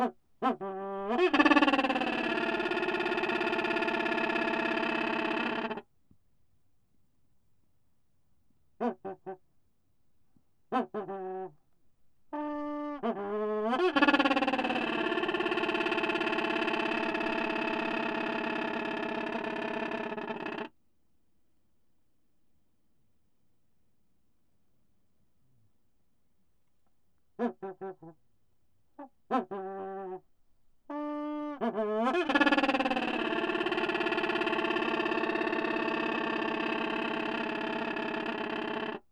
Procellaria cinerea - Petrel ceniciento.wav